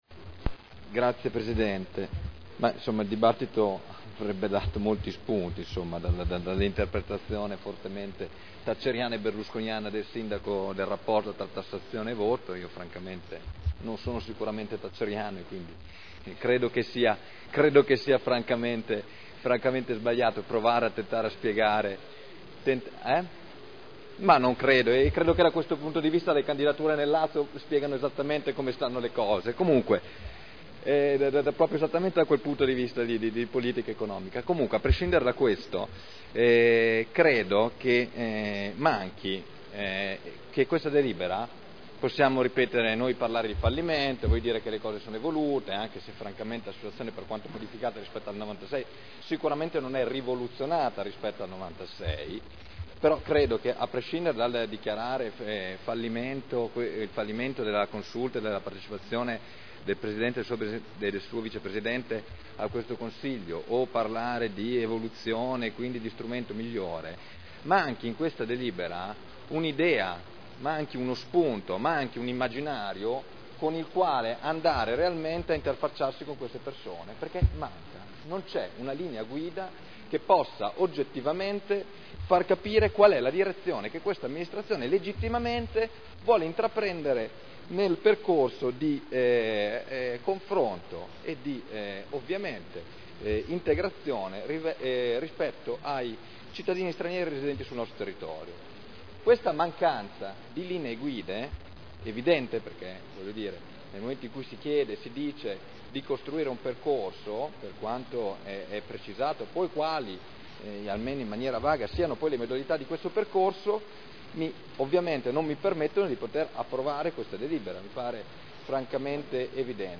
Michele Barcaiuolo — Sito Audio Consiglio Comunale
Dichiarazone di voto - Organismi di partecipazione e trasformazione sociale – Consulte afferenti le Politiche sociali, sanitarie e abitative (Commissione consiliare del 16 dicembre 2009)